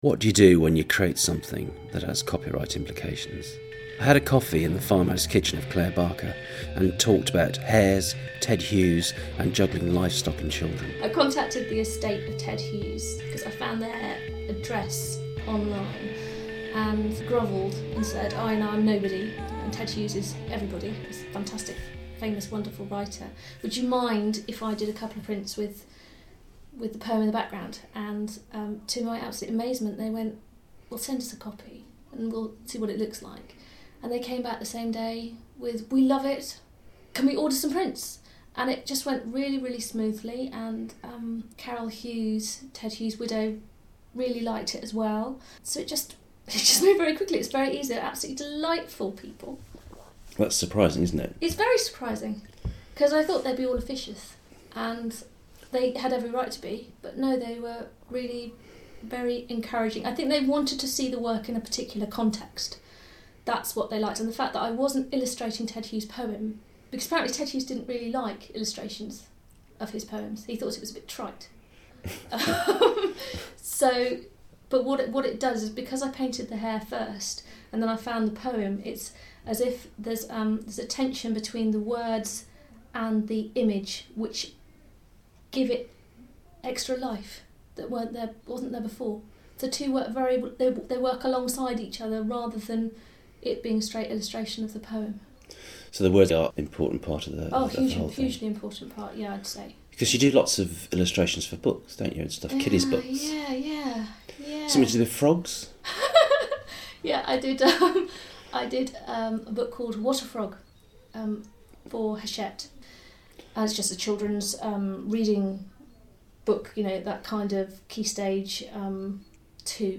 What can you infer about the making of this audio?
Interviews originally broadcast on SoundArt Radio (based in Dartington, Devon, UK)